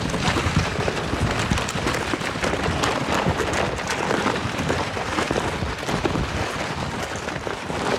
Sfx_creature_pinnacarid_slide_on_land_01.ogg